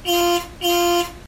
beetle_horn.ogg